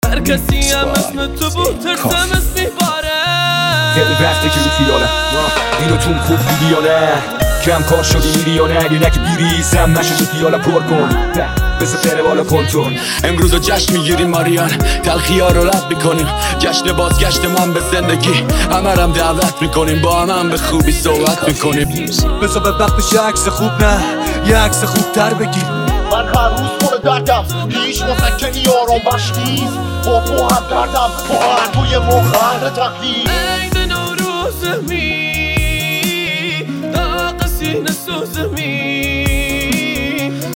اولین سازنده و منبع میکس های رپی
دموی این میکس را بشنوید !